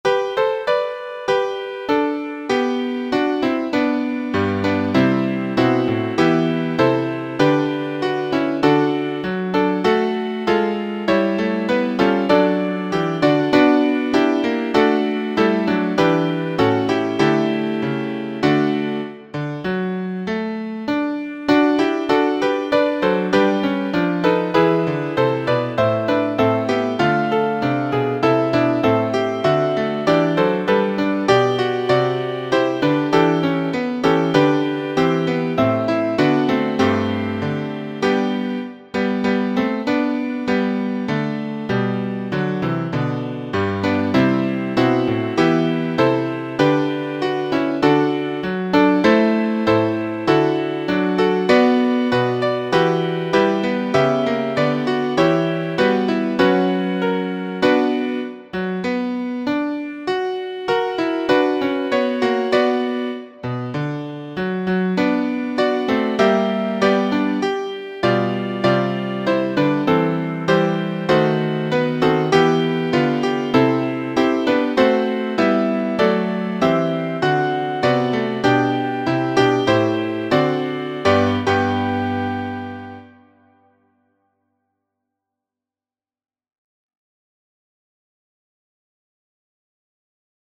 Title: Gather Ye Rosebuds Composer: Bruno Siegfried Huhn Lyricist: Robert Herrick Number of voices: 4vv Voicing: SATB Genre: Secular, Partsong
Language: English Instruments: A cappella